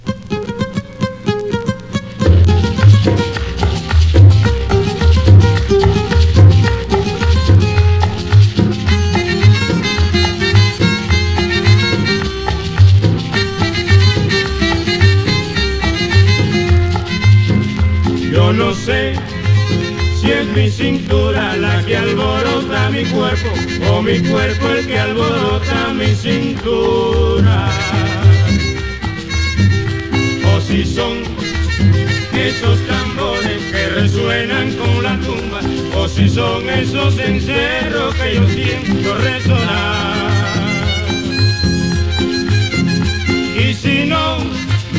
World, Latin, Son　Venezuela　12inchレコード　33rpm　Mono